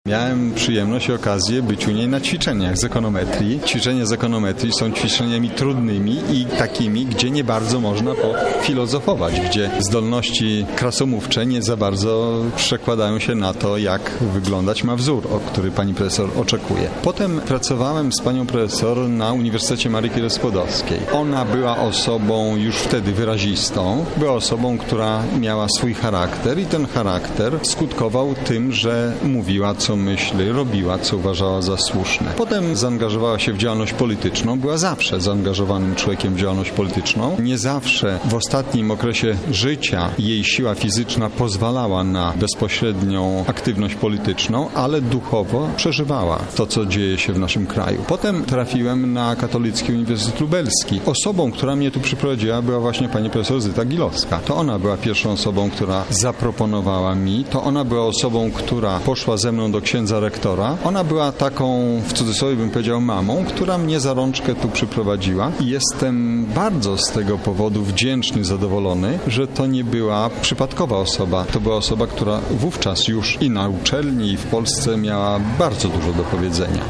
Pogrzeb Gilowskiej 3
Pogrzeb-Gilowskiej-3.mp3